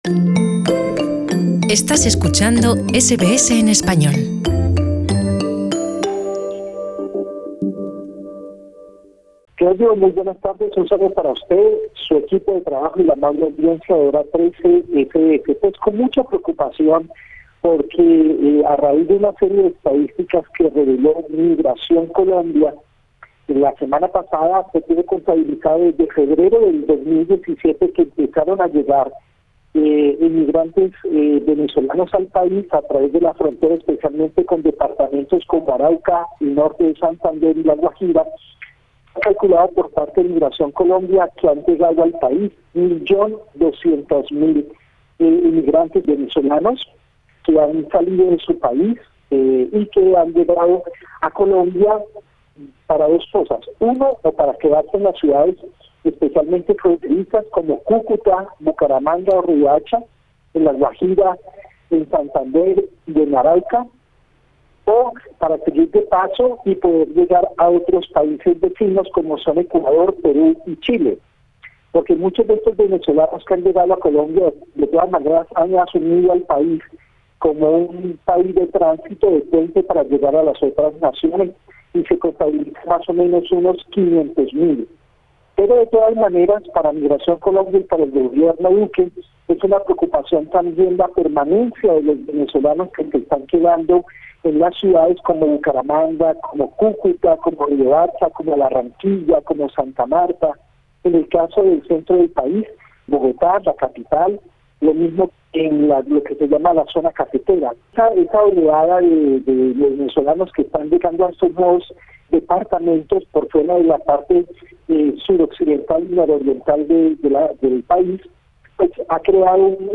Nuestro corresponsal en Colombia